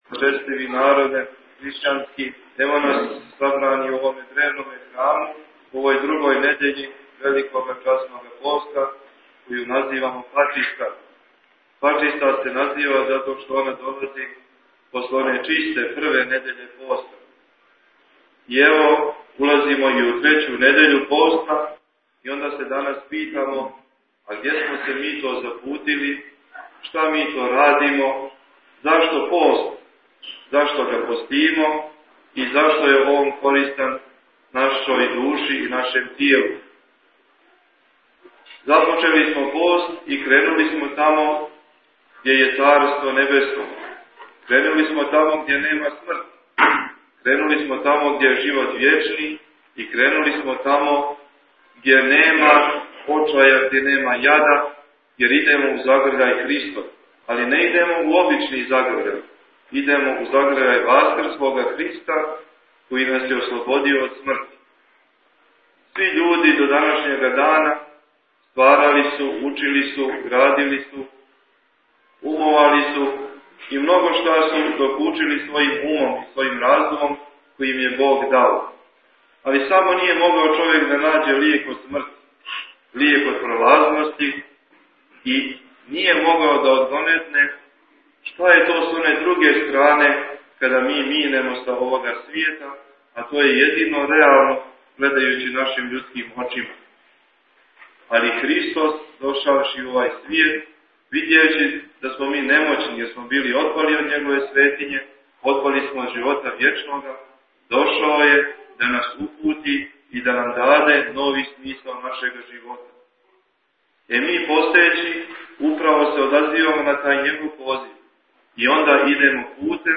Бесједa
изговорена на Светој Литургији у Храму Светог Архангела Михаила у Херцег Новом на другу недјељу Часног поста